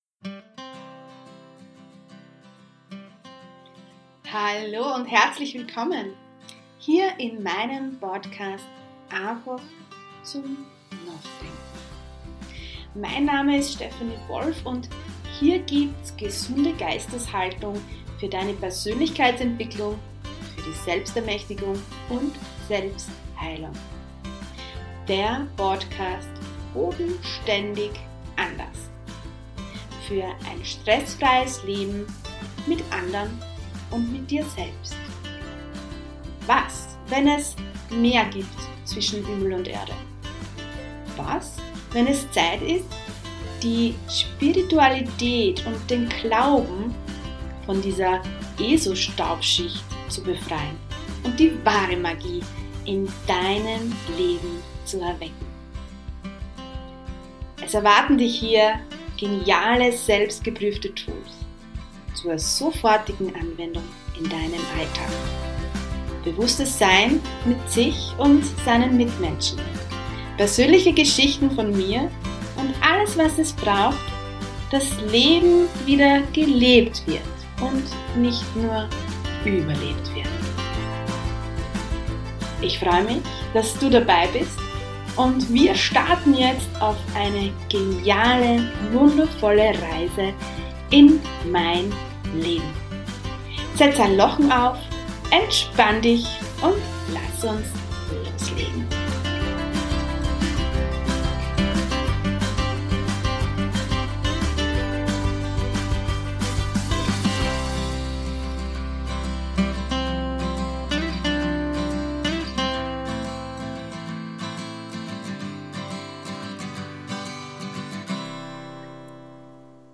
#26 Der Advent eine Einladung in die Stille, im Gespräch